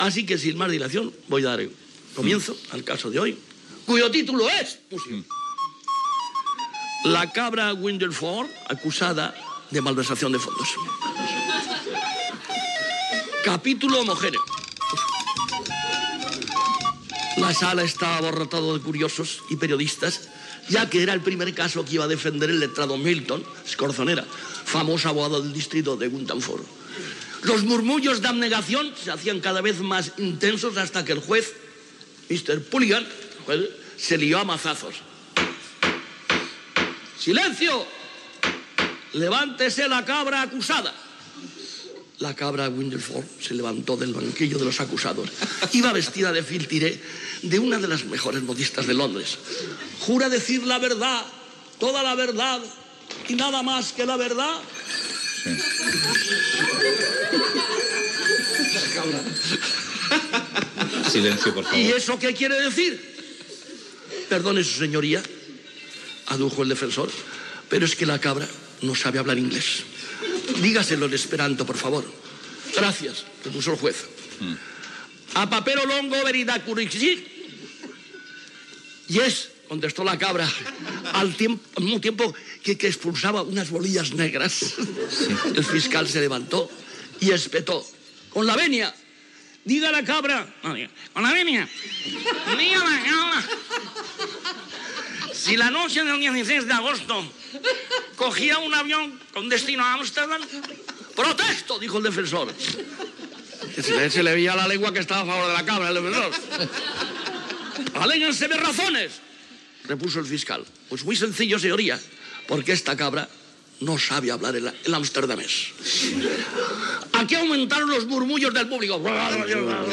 Fragment d'una intervenció humorística de Luis Sánchez Polack "Tip": el judici a una cabra.
Info-entreteniment
FM